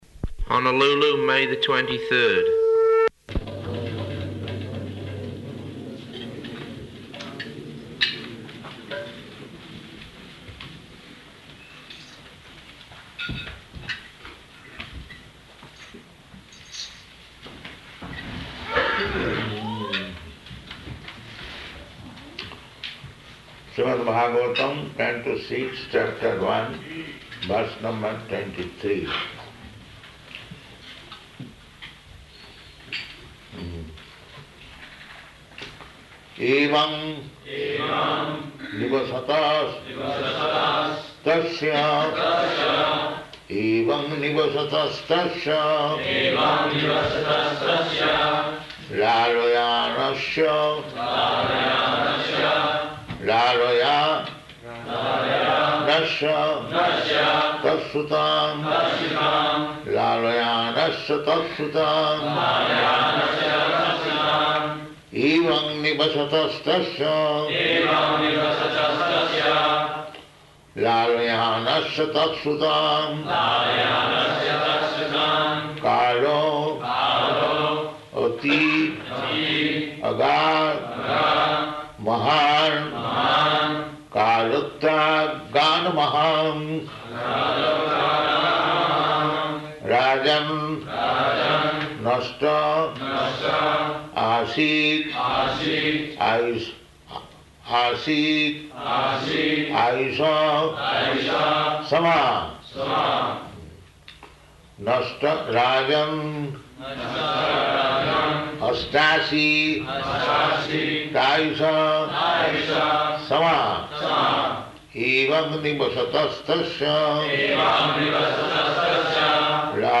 May 23rd 1976 Location: Honolulu Audio file
[leads devotees in chanting] evaṁ nivasatas tasya lālayānasya tat-sutān kālo 'tyagān mahān rājann aṣṭāśītyāyuṣaḥ samāḥ [ SB 6.1.23 ] [02:19] So in this way the Ajāmila, he practiced this unfair way of livelihood.